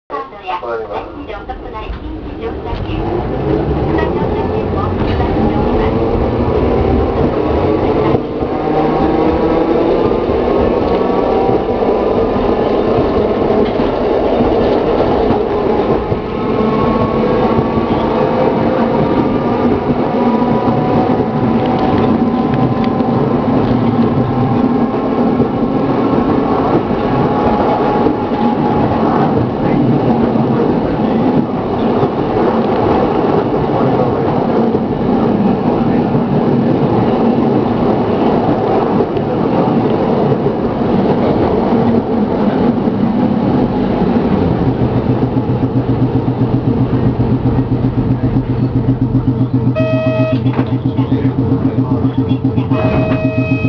・1350形走行音